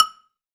BSYNAGOGOH.wav